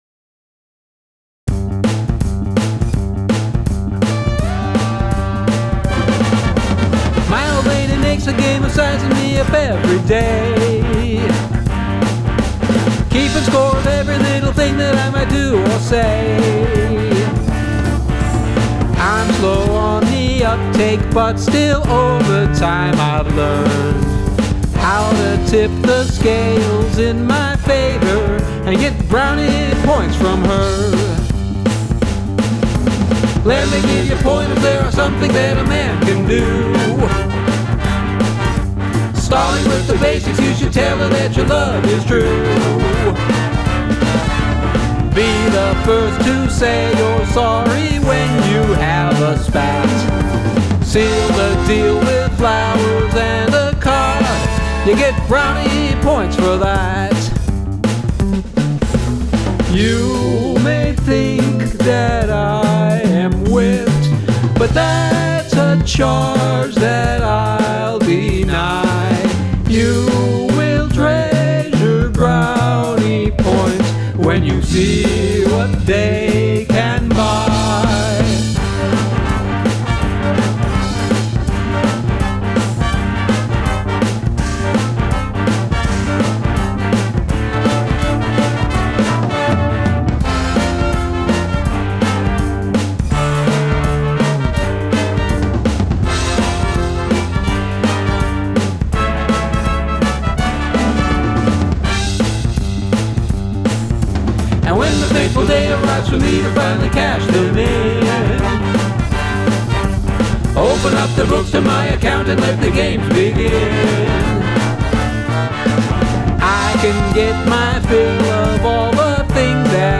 Time Signature Changes